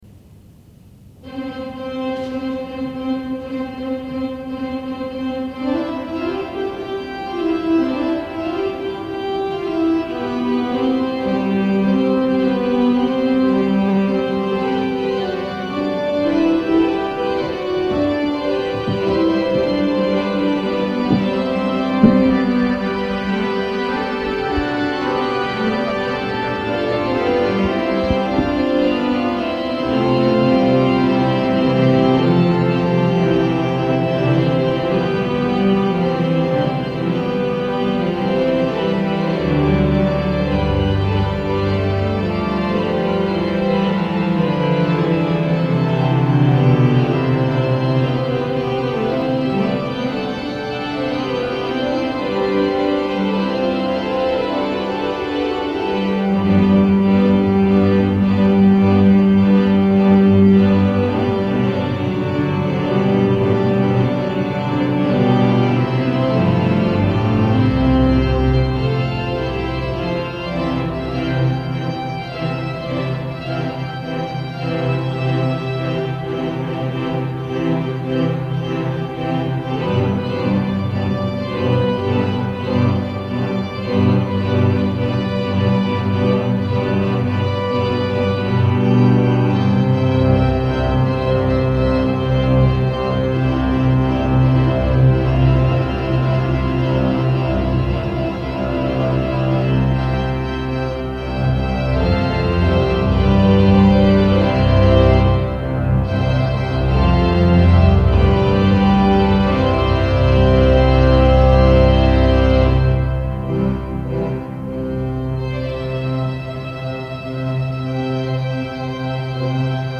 interpretó al órgano OESA del Valle de los Caídos: